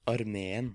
In the second example, the last syllable is stressed.